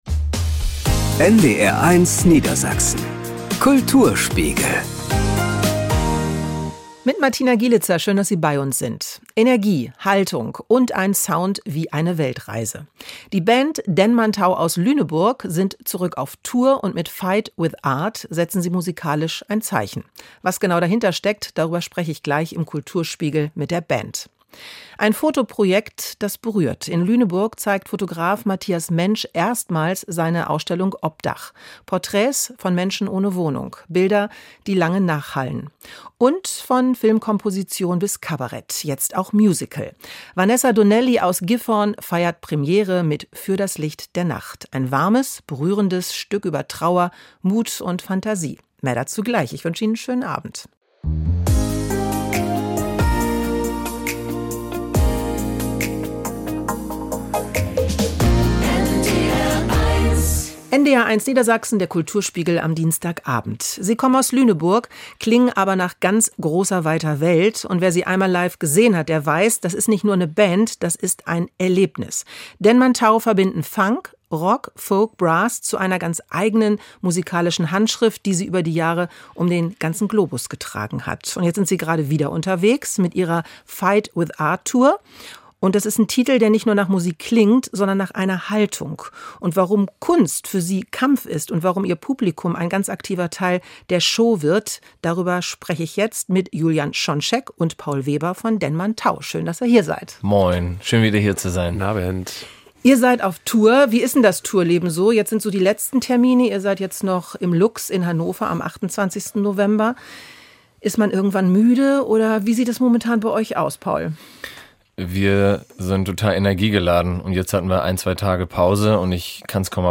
Zu Gast im Studio: Denmantau ~ NDR 1 Niedersachsen - Kulturspiegel Podcast